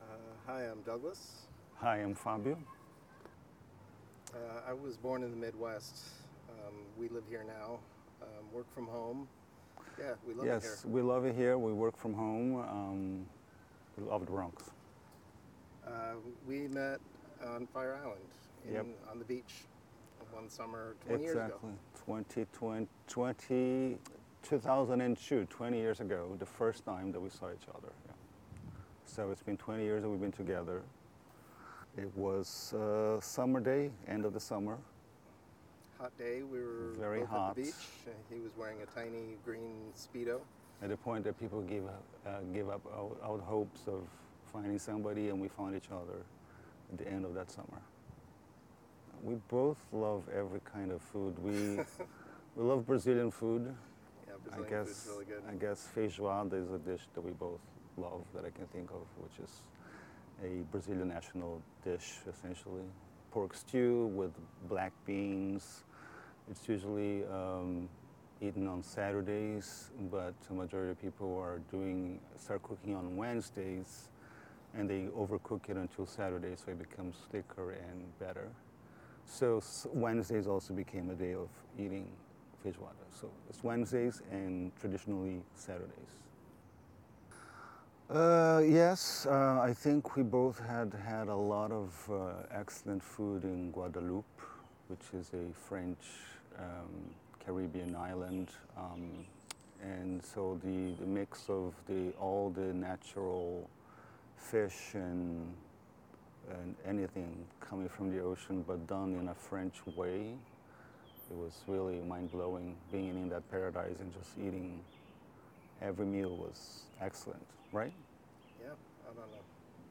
A link to the audio files recorded by the couples is also available at the table, where you are invited to sit, listen, and reminisce about your own love stories and food memories.